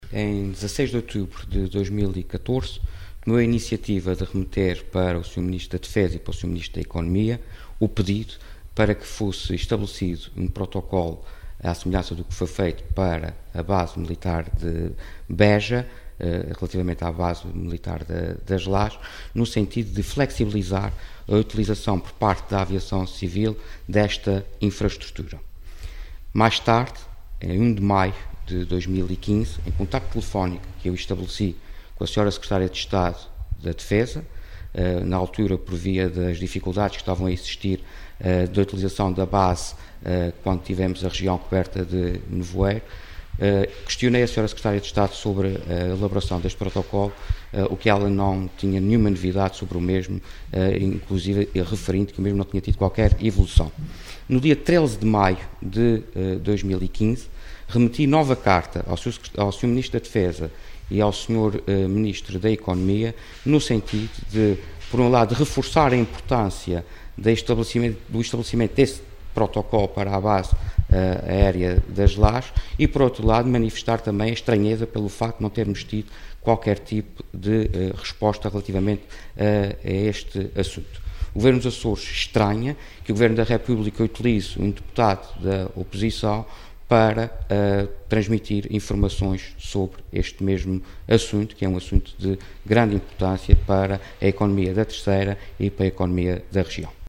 Vítor Fraga, em declarações aos jornalistas, lembrou que o Governo dos Açores, a 16 de outubro de 2014, tomou a iniciativa de remeter para os ministros da Defesa e da Economia o pedido para que fosse estabelecido um protocolo, à semelhança do que foi feito para a Base Militar de Beja, relativamente à Base Militar das Lajes, no sentido de flexibilizar a sua utilização pela aviação civil.